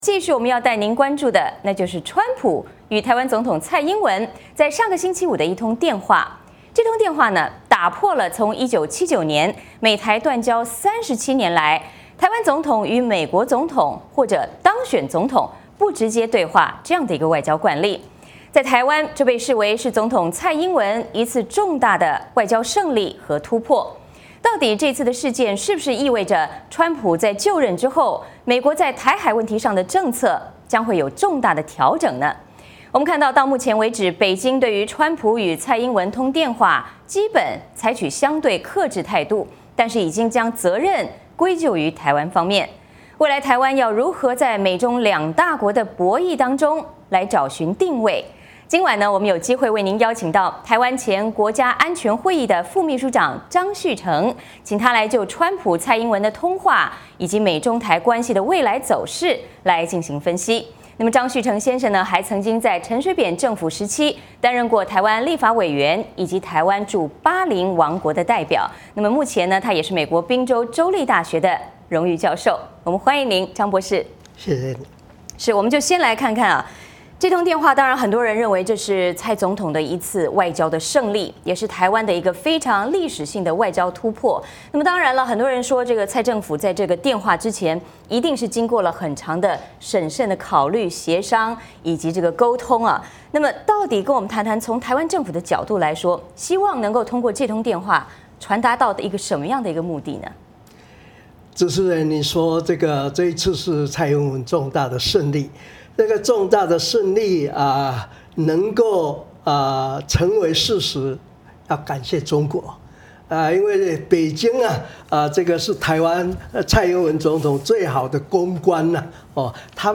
VOA连线: VOA卫视专访前台湾国安会副秘书长张旭成